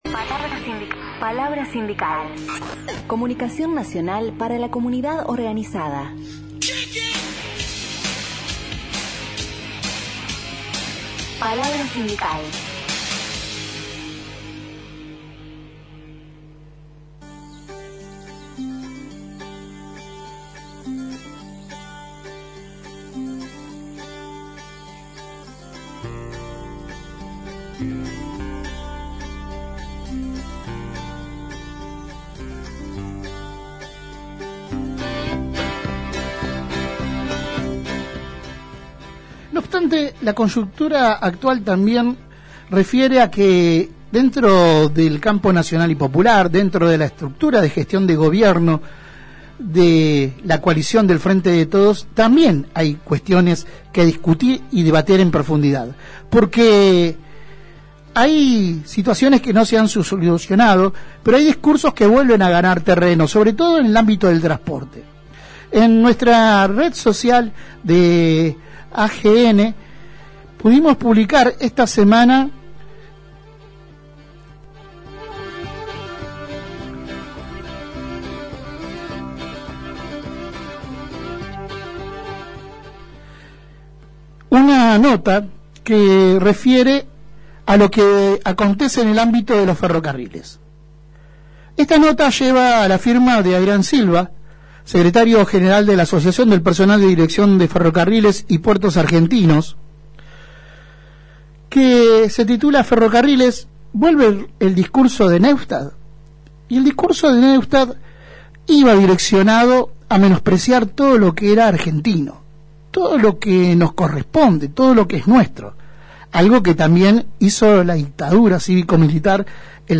Comaprtimos la entrevista completa: